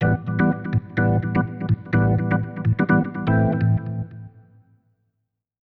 ORGAN010_VOCAL_125_A_SC3(L).wav